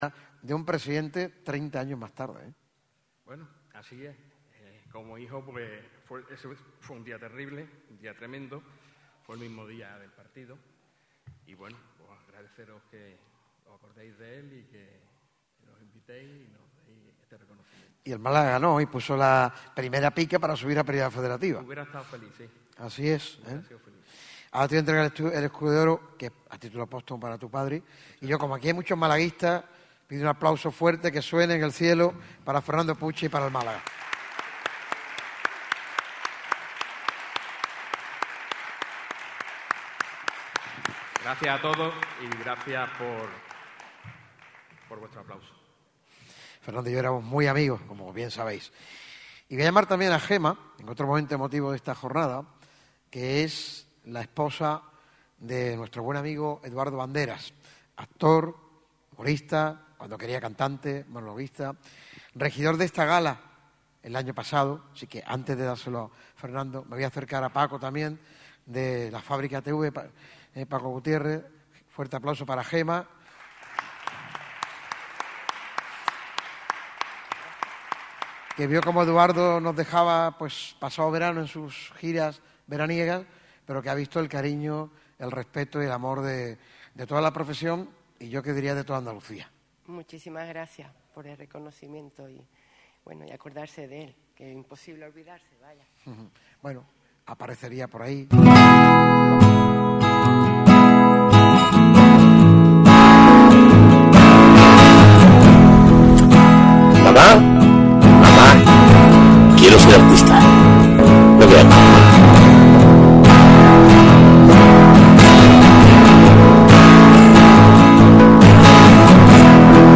Por ello, el micrófono rojo lo celebrará junto a numerosos invitados y protagonistas de excepción en el ya habitual y tradicional escenario del Auditorio Edgar Neville de la Diputación de Málaga y con el patrocinio de Mango TROPS.